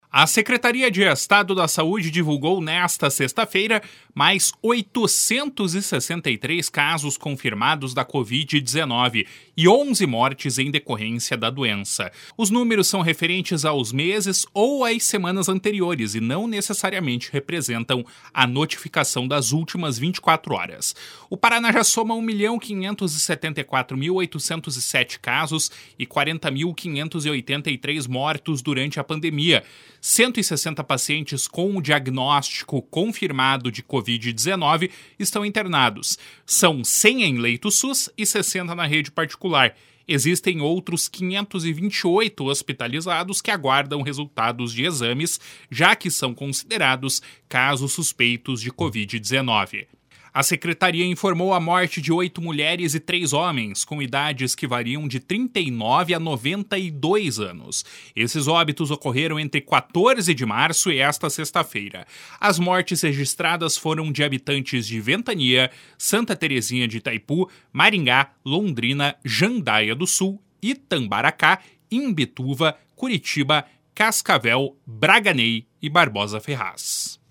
(Repórter: